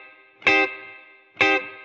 DD_TeleChop_130-Cmin.wav